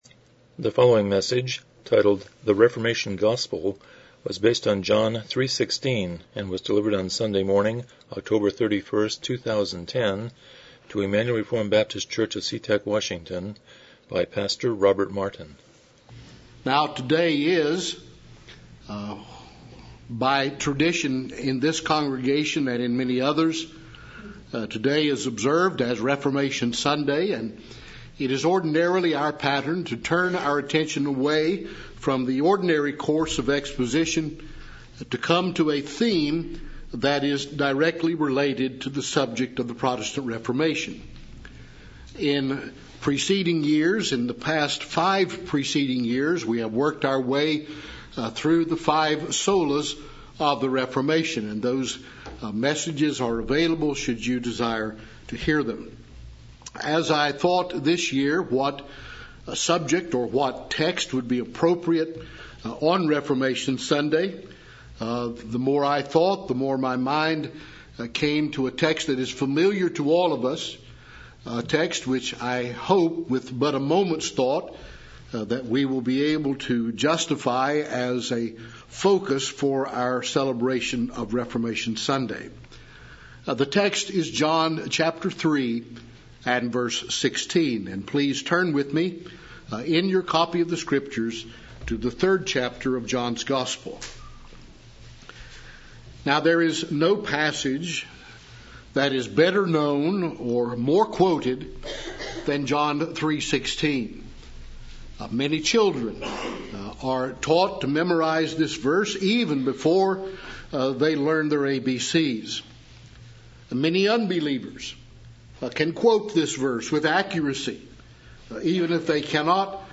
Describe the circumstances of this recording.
Passage: John 3:16 Service Type: Morning Worship